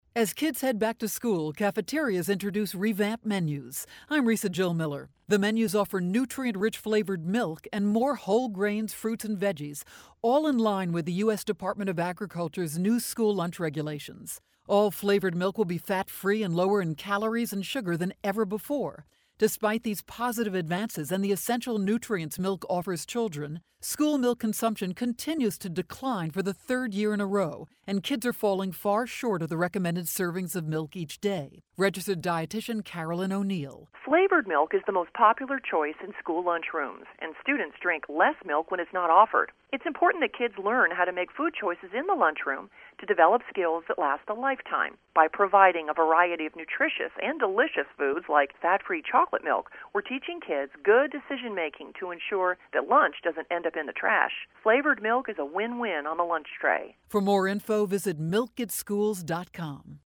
August 28, 2012Posted in: Audio News Release